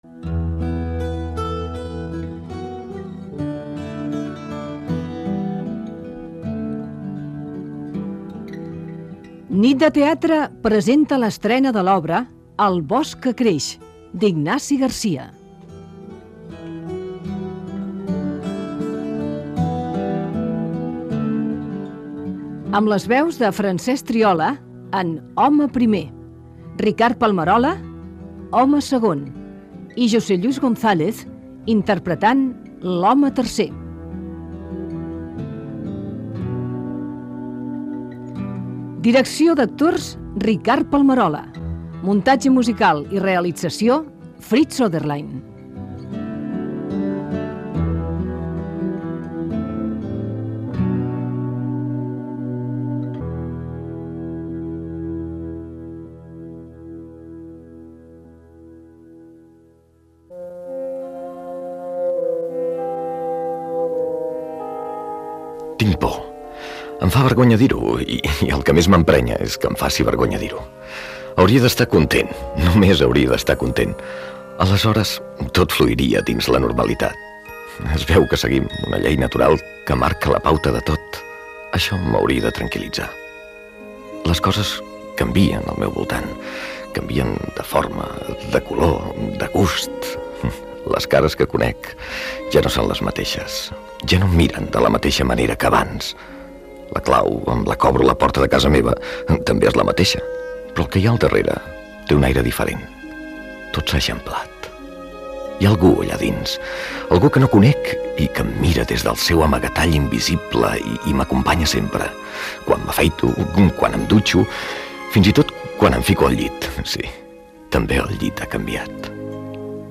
Presentació i adaptació radiofònica de l'obra "El bosc que creix", d'Ignasi García Gènere radiofònic Ficció